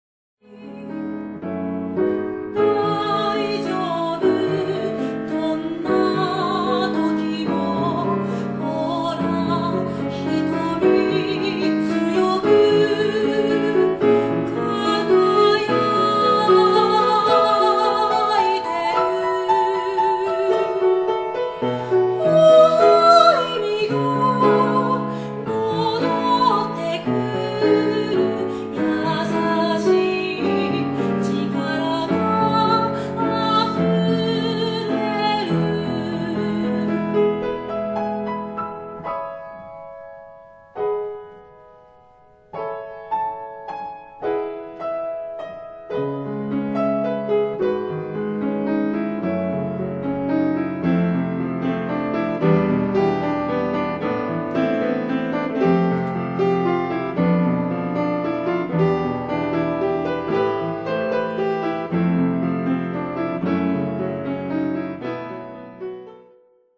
フランスの薫りが漂うこの曲は、私なりの２人へのメッセージ。